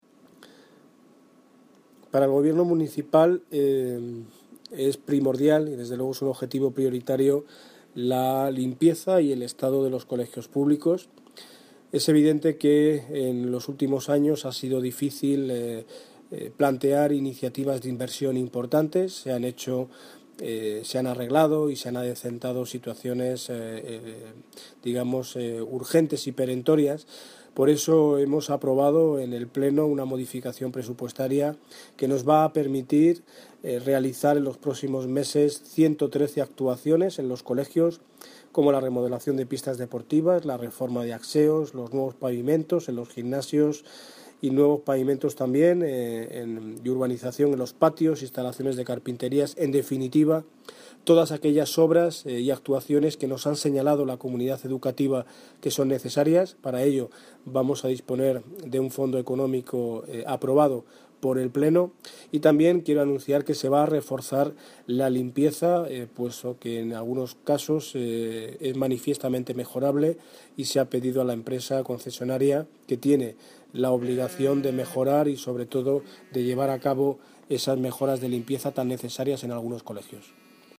Audio - Daniel Ortiz (Alcalde de Móstoles) Sobre Limpieza colegios